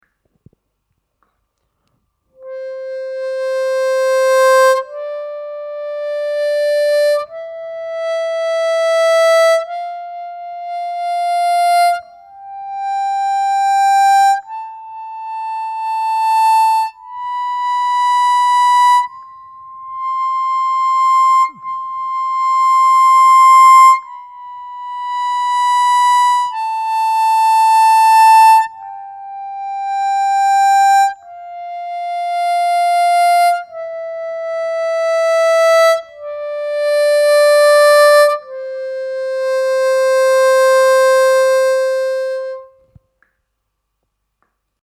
Играть отдельные одиночные ноты, с помощью техники блокировки языком, а затем играть гамму «до мажор» таким же способом: